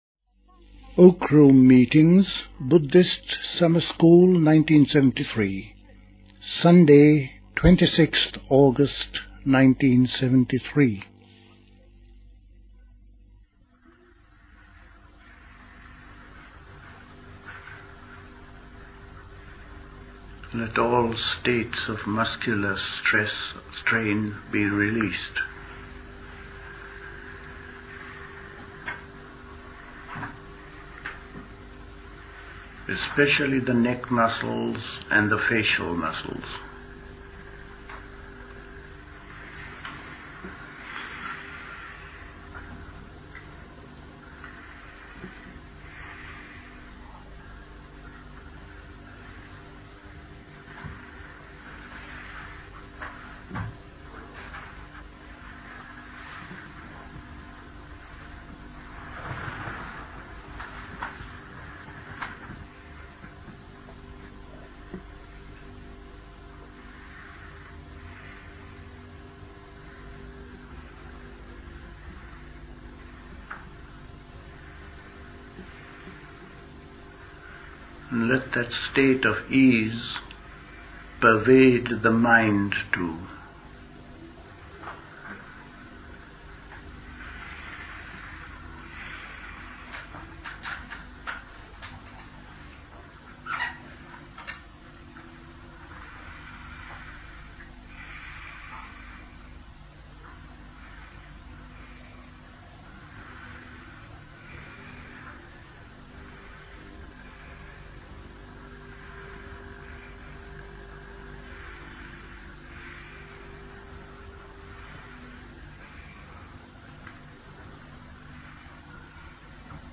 Recorded at the 1973 Buddhist Summer School.